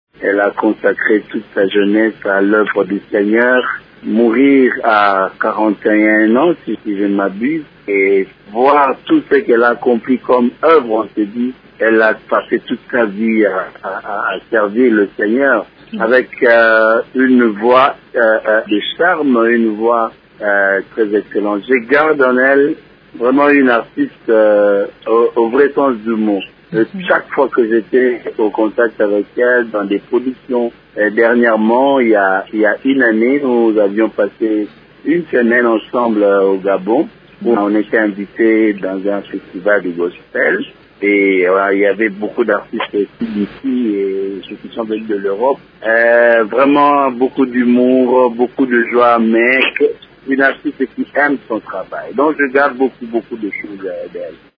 Ecoutez la suite du témoignage de Franck Mulaja après la mort de Marie Misamu ici: